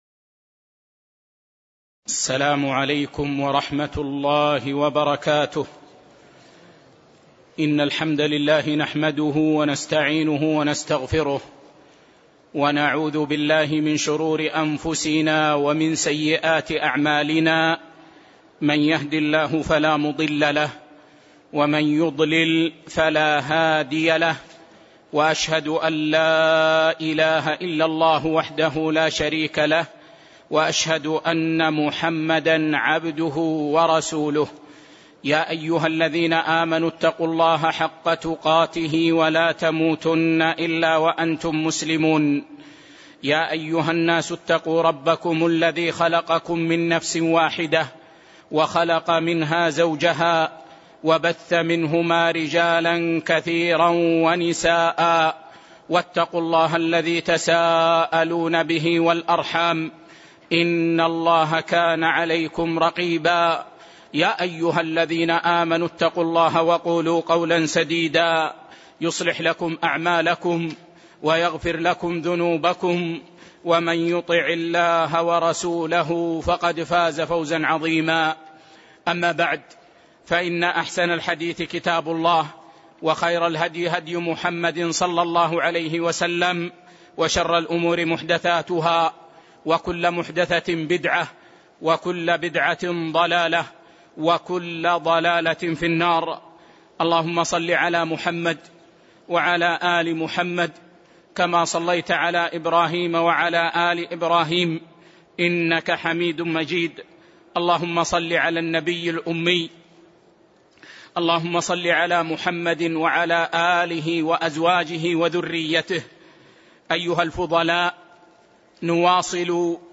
تاريخ النشر ٢٧ محرم ١٤٣٨ هـ المكان: المسجد النبوي الشيخ